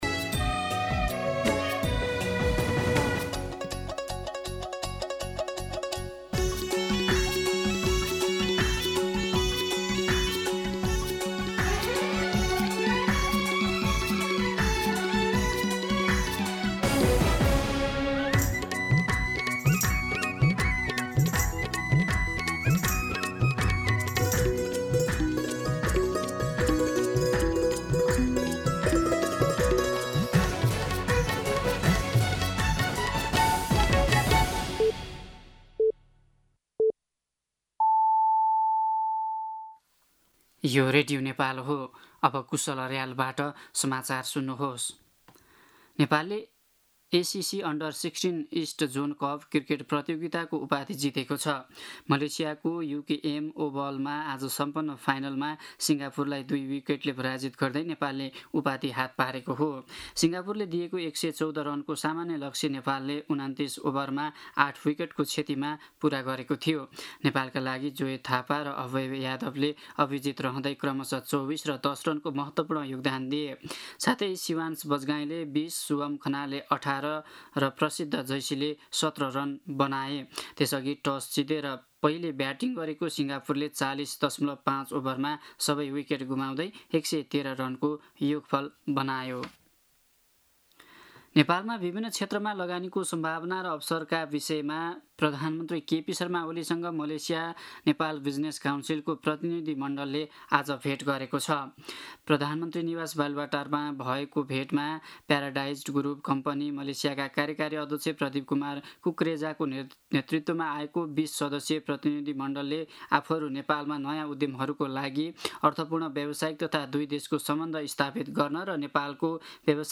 दिउँसो ४ बजेको नेपाली समाचार : २१ असार , २०८२